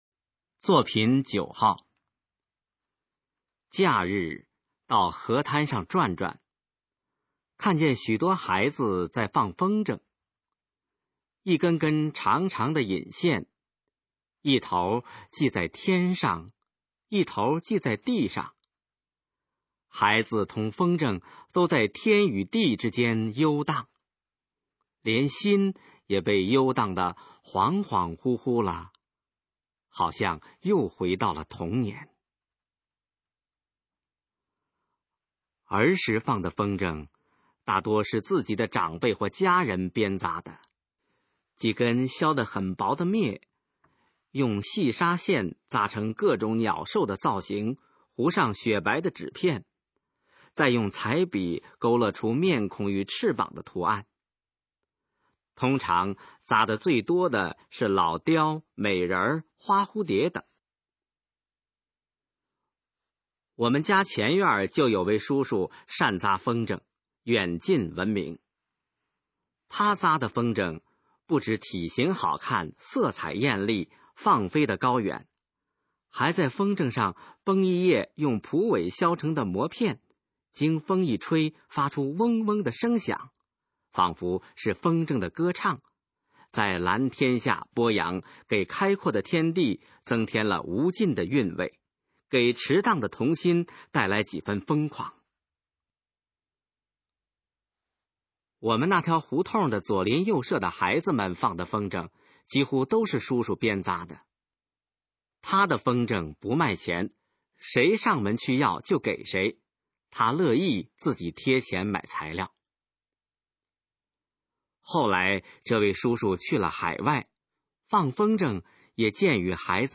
首页 视听 学说普通话 作品朗读（新大纲）
《风筝畅想曲》示范朗读_水平测试（等级考试）用60篇朗读作品范读　/ 佚名